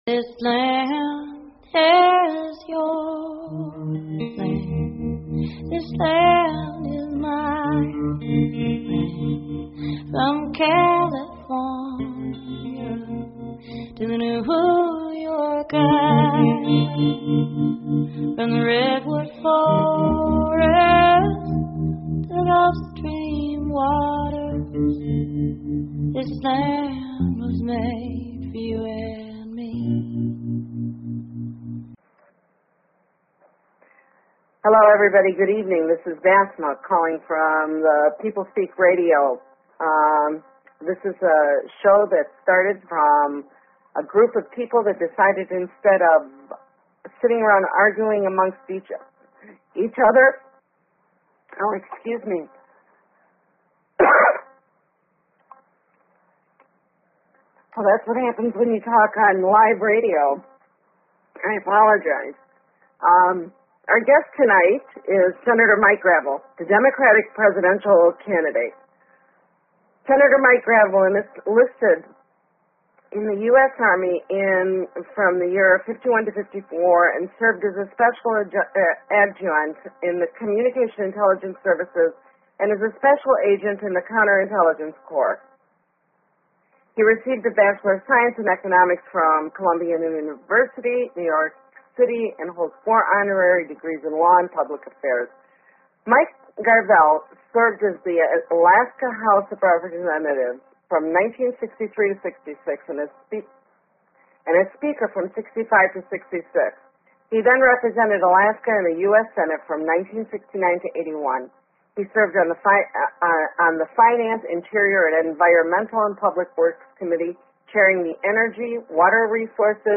Talk Show Episode, Audio Podcast, The_People_Speak and Mike Gravel on , show guests , about , categorized as
Guest, Mike Gravel